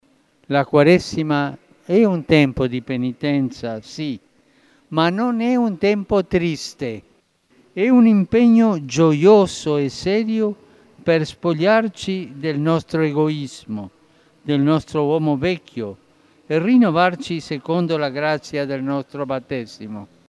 W rozważaniach przed modlitwą Anioła Pański papież przypomniał, że Wielki post jest czasem duchowego zmagania się.